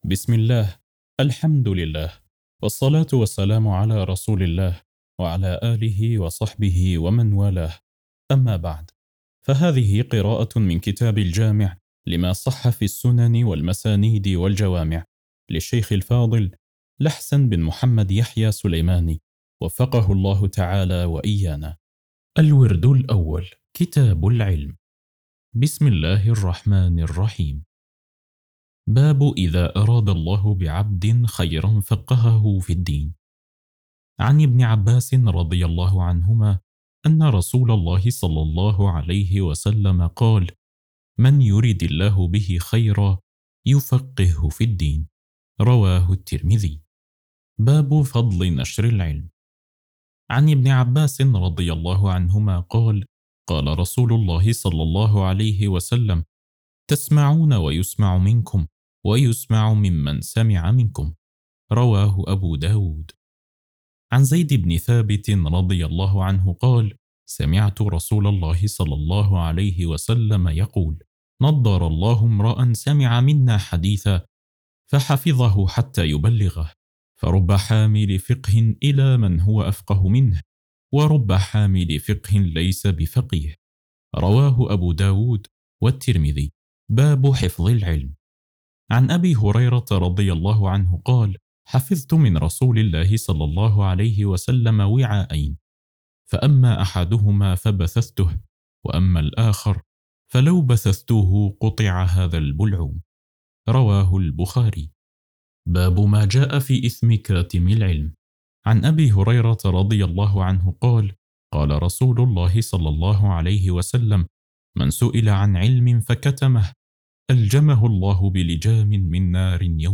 قراءة كتاب: الجامع لما صح في السنن والمسانيد والجوامع